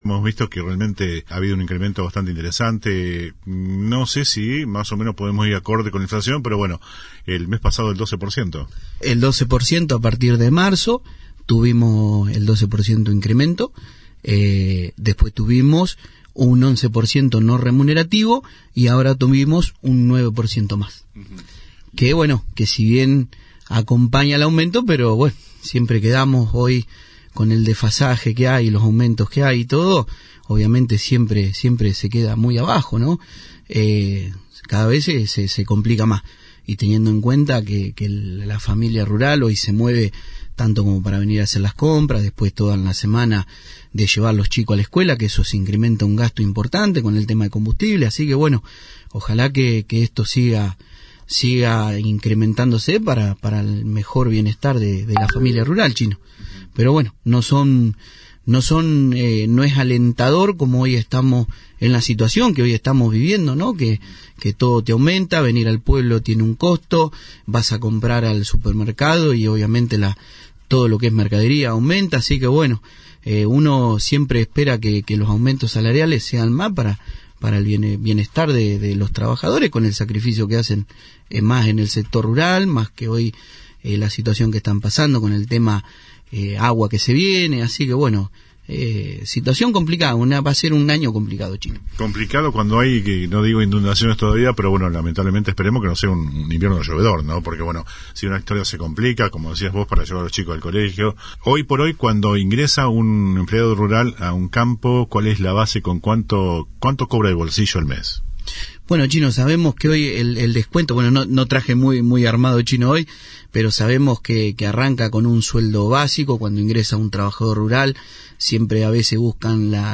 En la mañana del viernes conversamos en “El Periodístico”